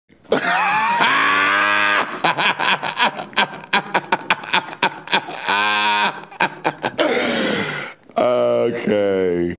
Latrice Royale Laughing
More Sounds in Laugh Soundboard
latrice-royale-laughing.mp3